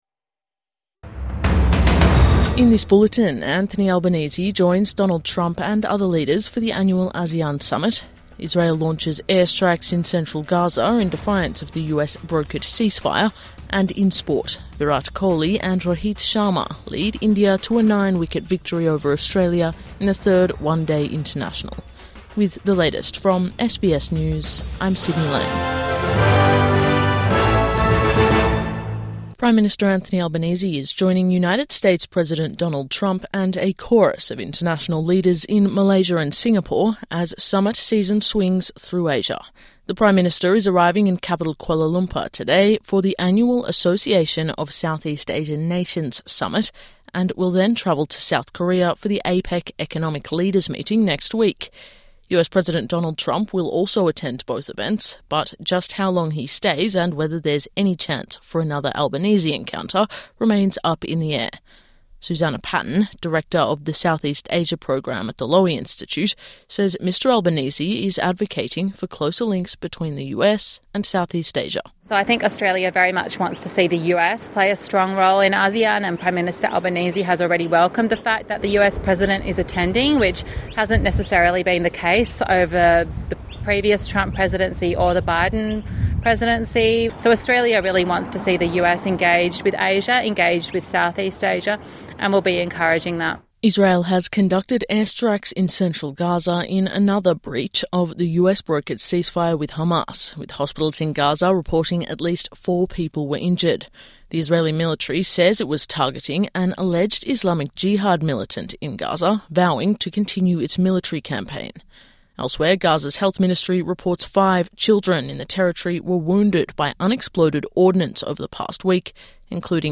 Morning News Bulletin 26 October 2025